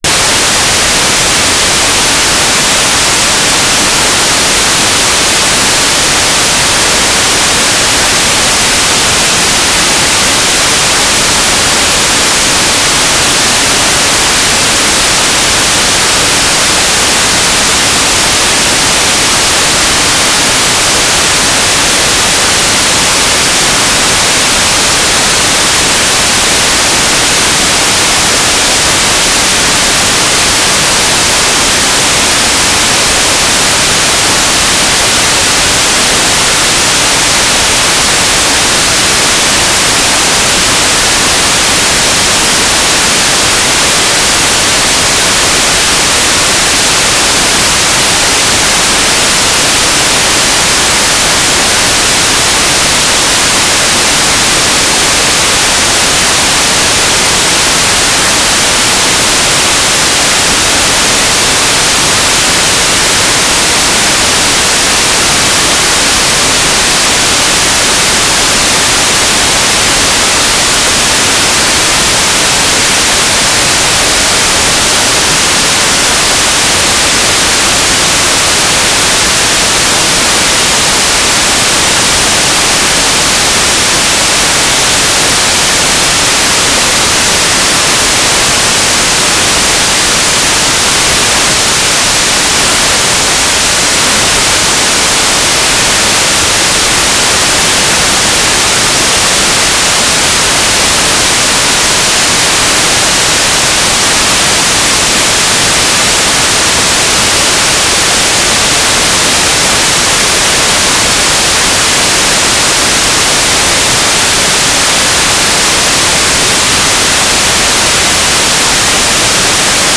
"transmitter_description": "S-band telemetry",
"transmitter_mode": "FMN",